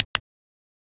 click-short-confirm.wav